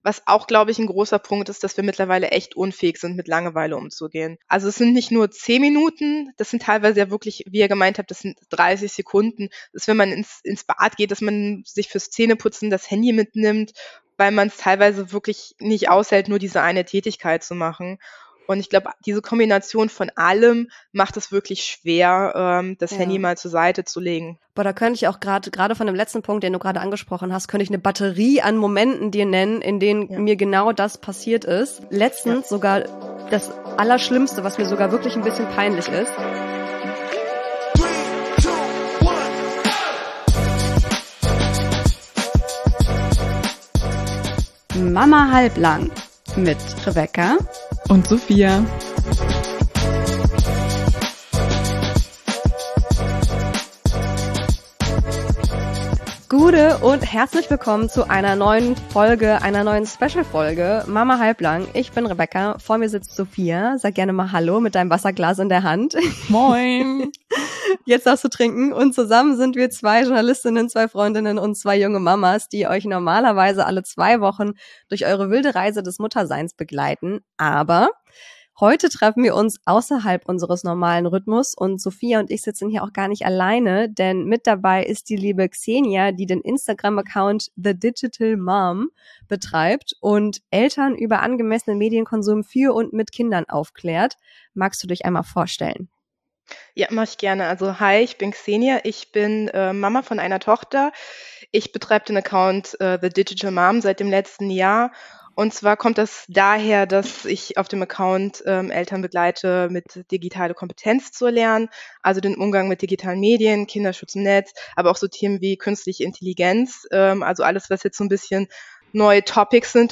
Wie viel Bildschirmzeit für die Kleinen okay ist – Interview mit Digitalberaterin